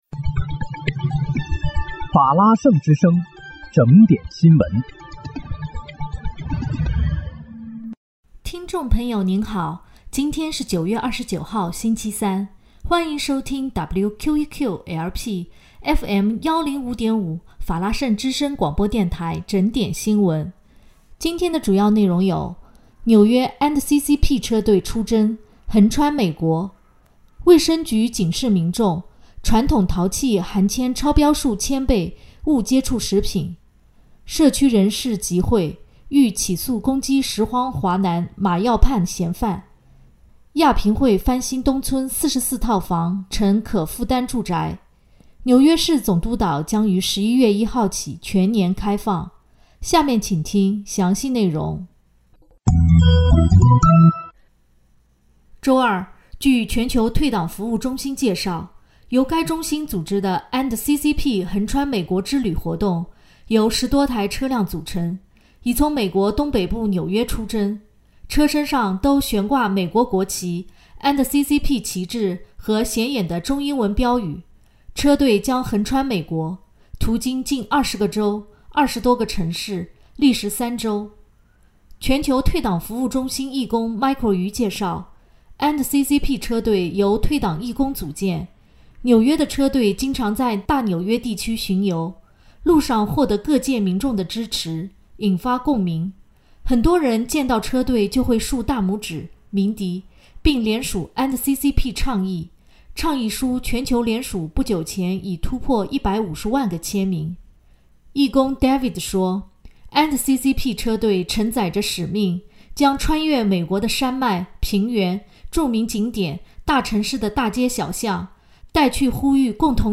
9月29日（星期三）纽约整点新闻